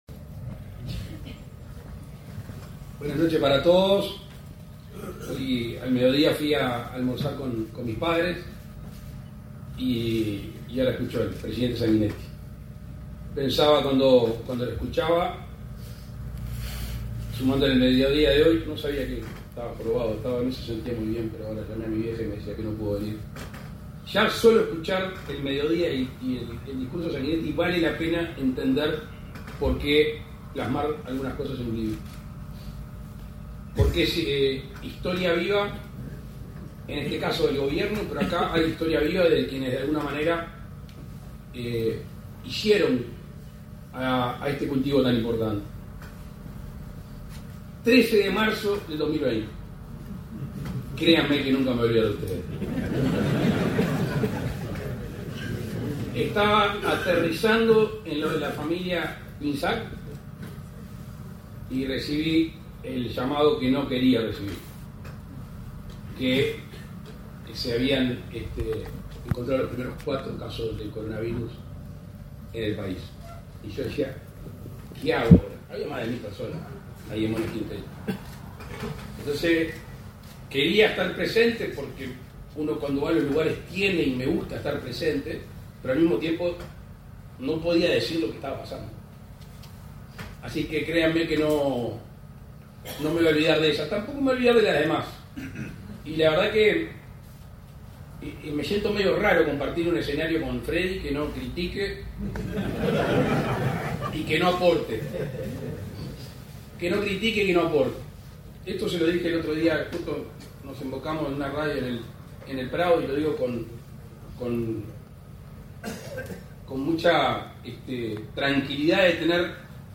Palabras del presidente de la República, Luis Lacalle Pou
El presidente de la República, Luis Lacalle Pou, participó, este 18 de setiembre, en el lanzamiento de un libro de la Asociación de Cultivadores de